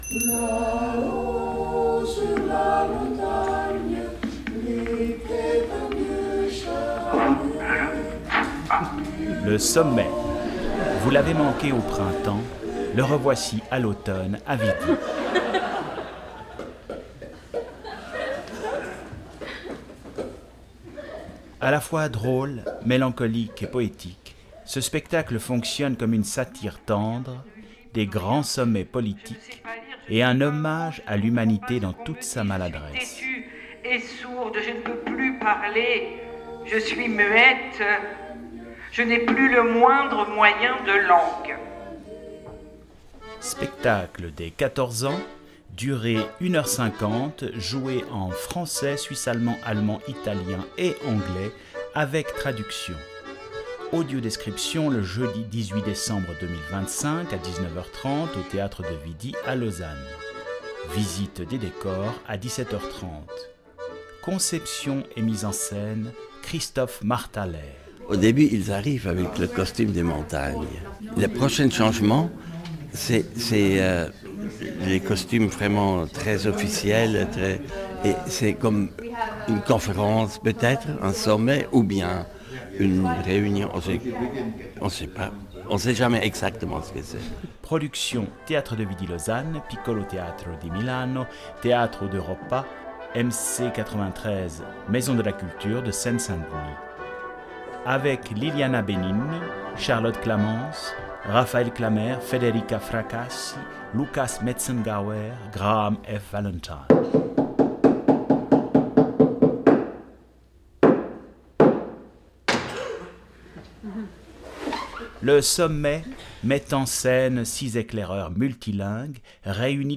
Audiodescription
Bande annonce